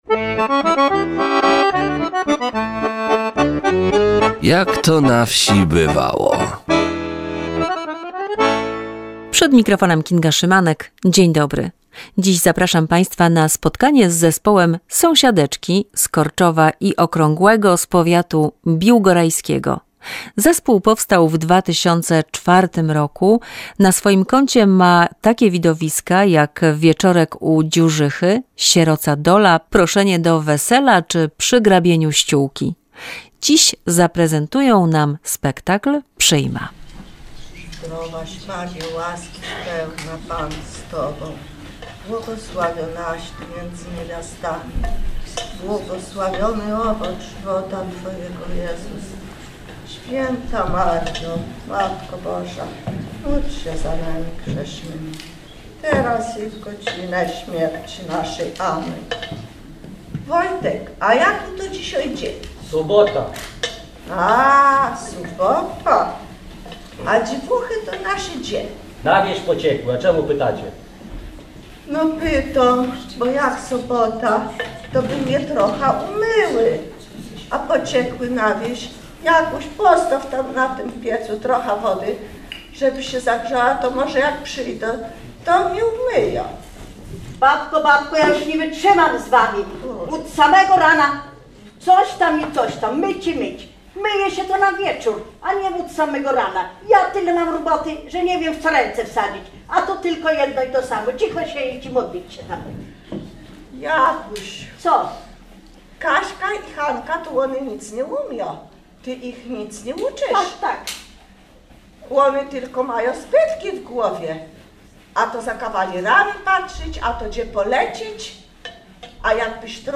Spektakl "Przyjma" w wykonaniu zespołu Sąsiadeczki z Korczowa i Okrągłego.